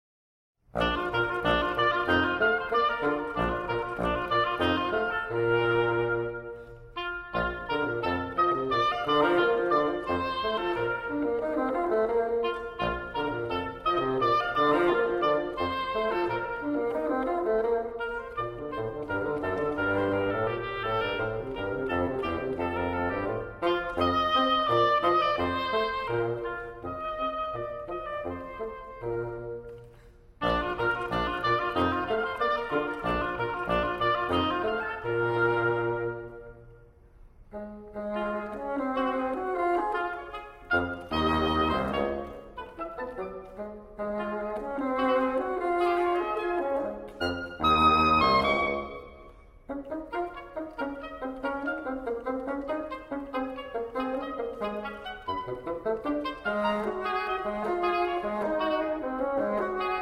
oboe